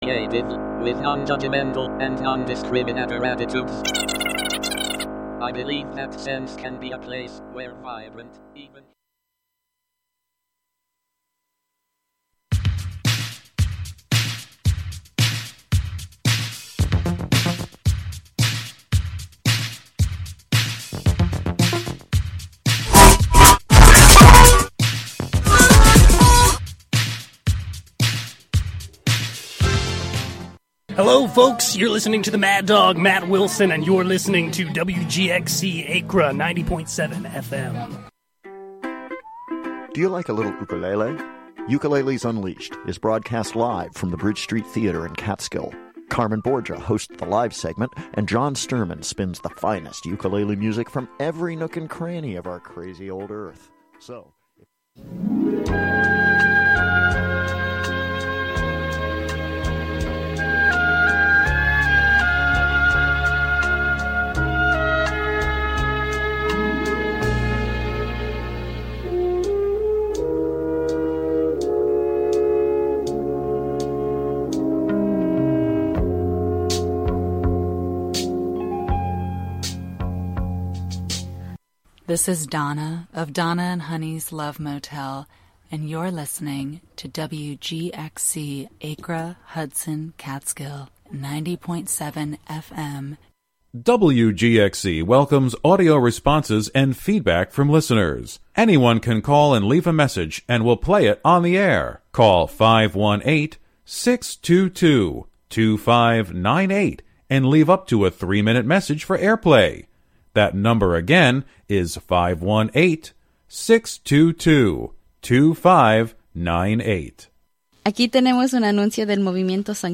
8pm Local raver and DJ
brings you sounds from raves and clubs around the world